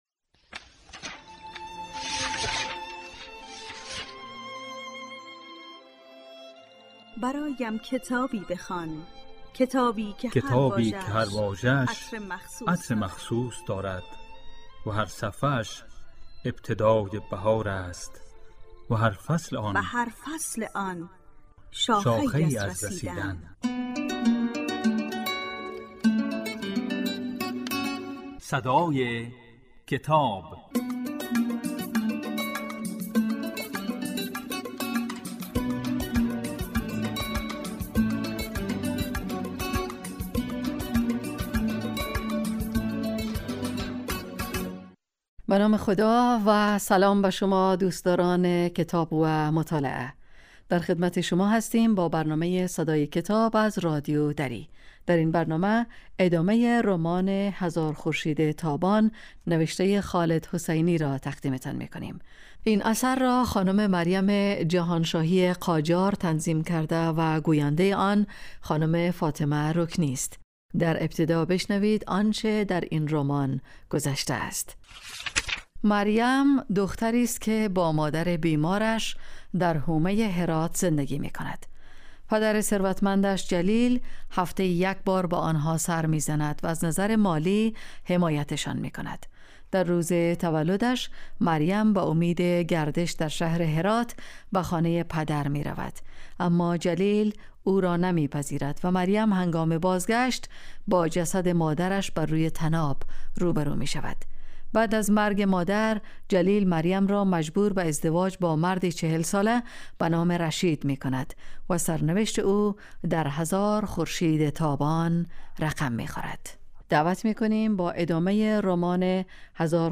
در این برنامه، به دنیای کتاب‌ها گام می‌گذاریم و آثار ارزشمند را می‌خوانیم.